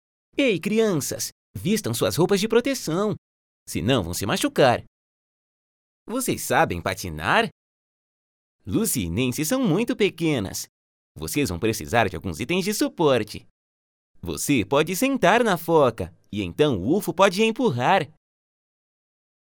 Locutores de doblaje de Brasil